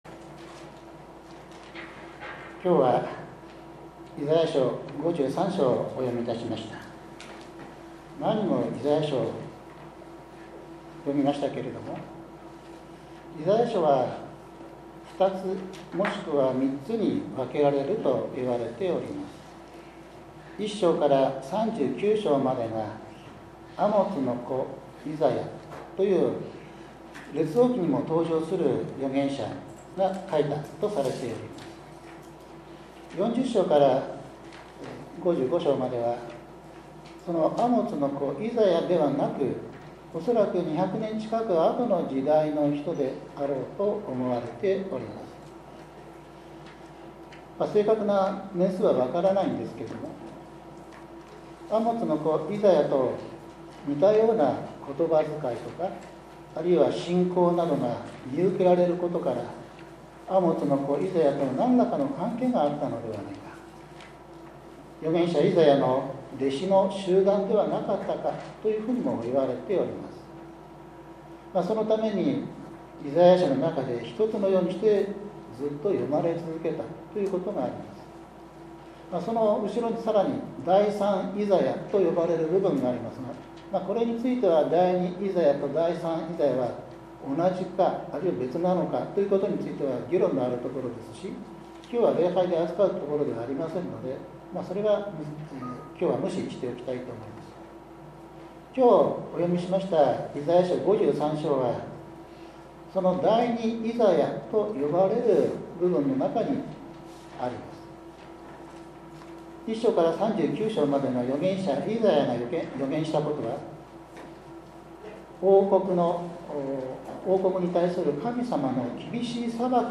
１１月３日（日）主日礼拝 イザヤ書５３章１節～１２節 使徒言行録８章２６節～３８節